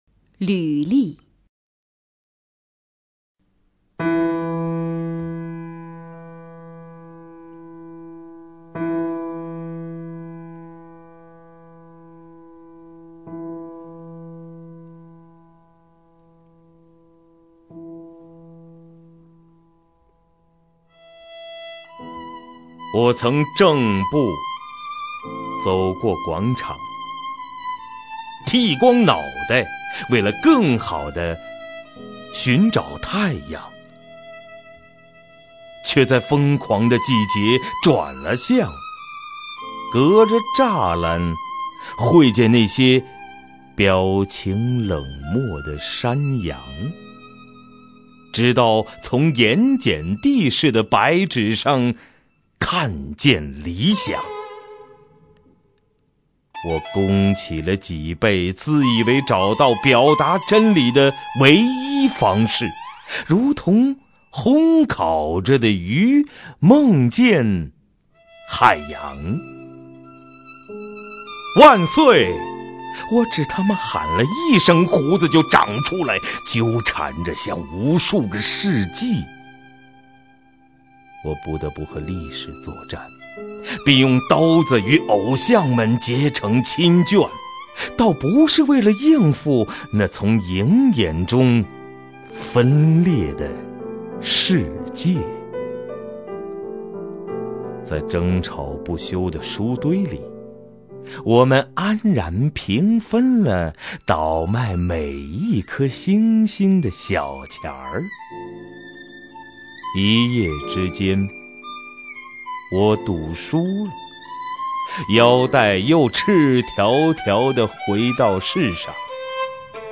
首页 视听 名家朗诵欣赏 赵屹鸥
赵屹鸥朗诵：《履历》(北岛)